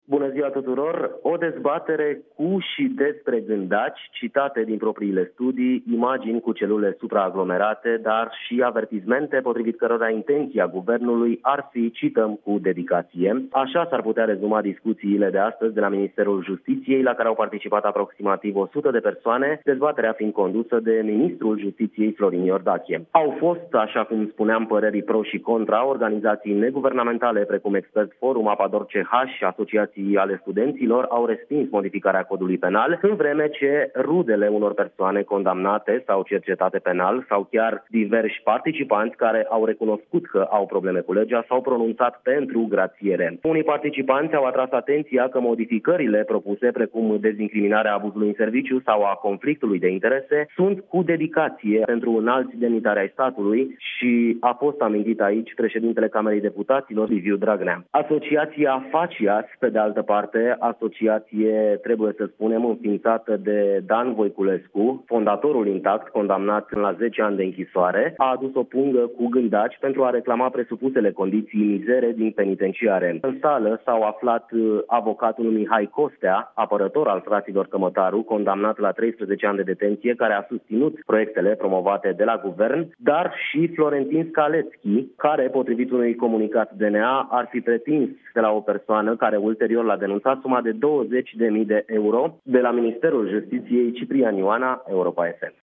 Corespondentul Europa FM